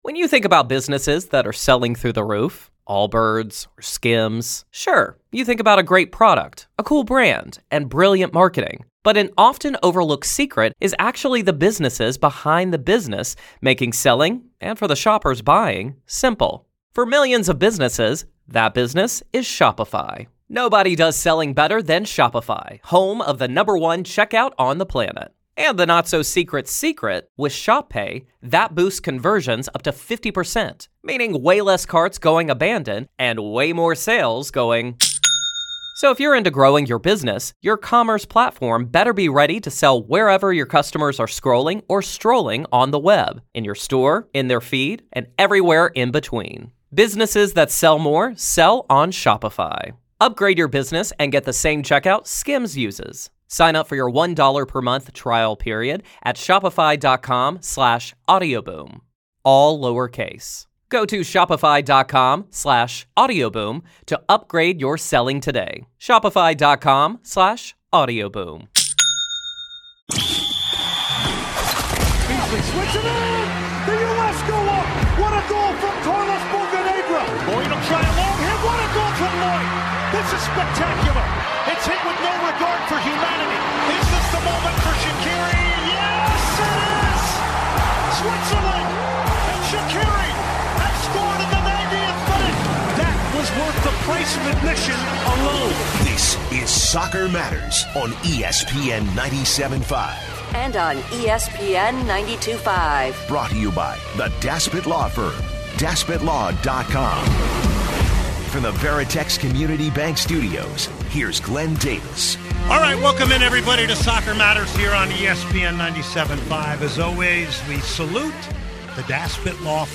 a passionate discussion on the state of the Dynamo and the future.